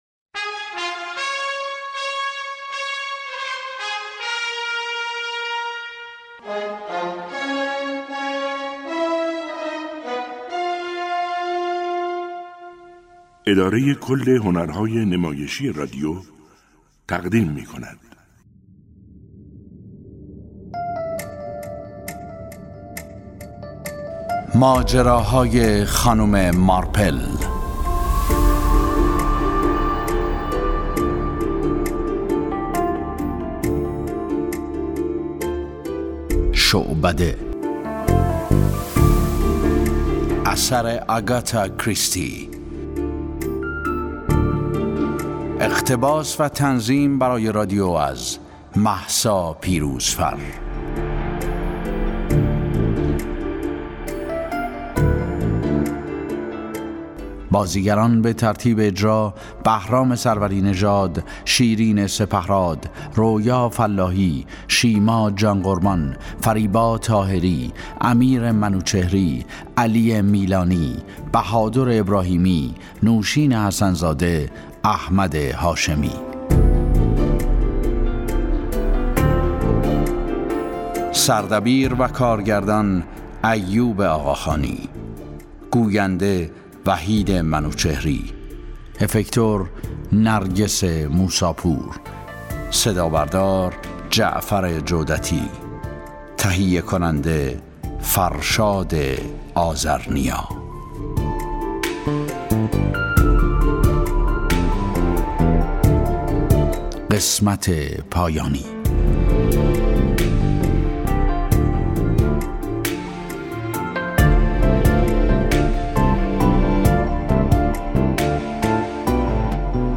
یکی از این آثار نمایش رادیویی «شعبده» است که در ۵ قسمت تولید و پخش شده است.